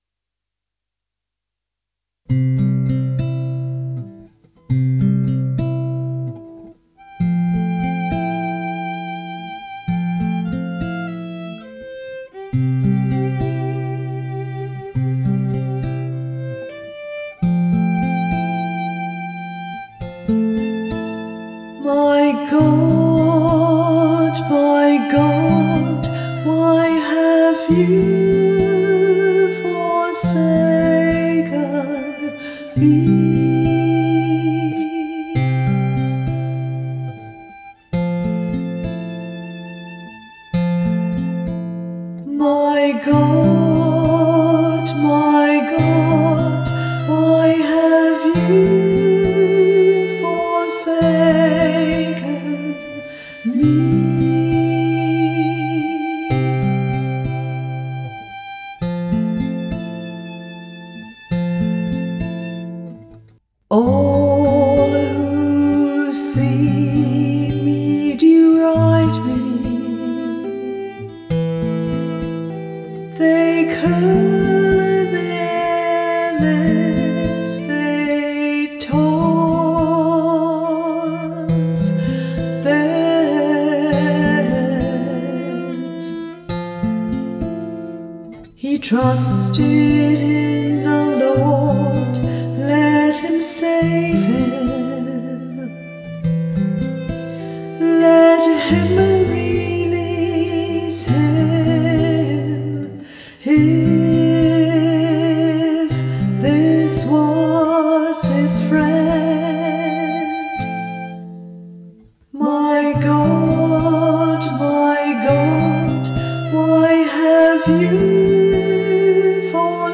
The music for the psalm is an original OLOR composition.
Music by the Choir of Our Lady of the Rosary RC Church, Verdun, St. John, Barbados.